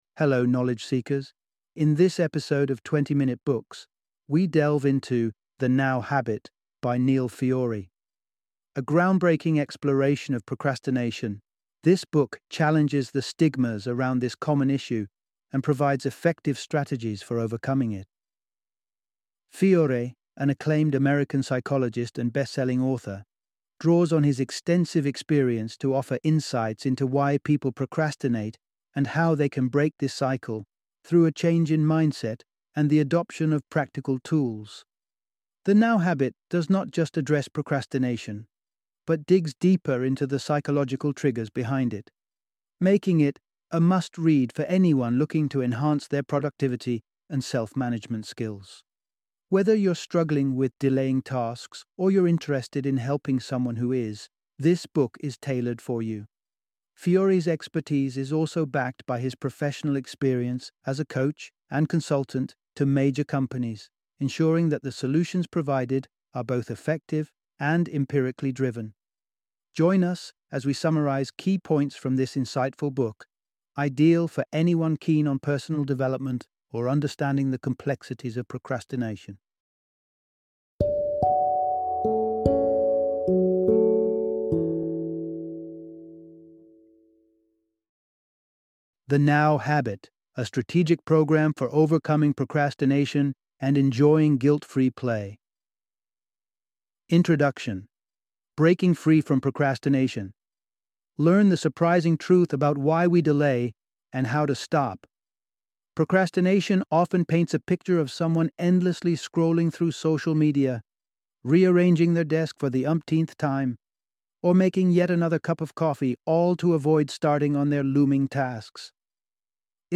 The Now Habit - Audiobook Summary